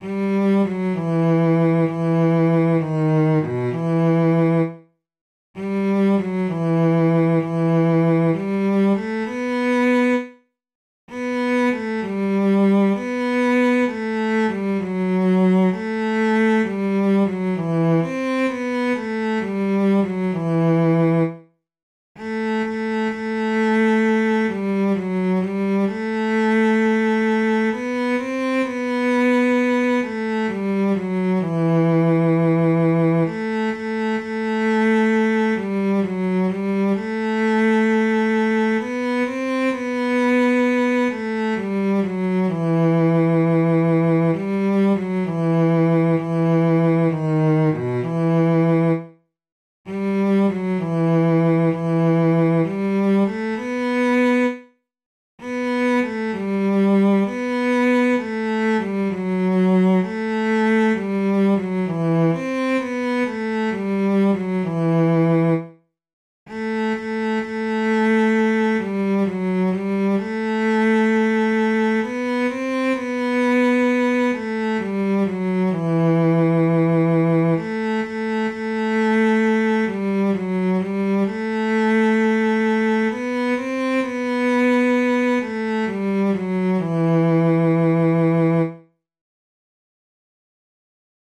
Jewish Folk Song for Pesach (Passover)
E minor ♩= 65 bpm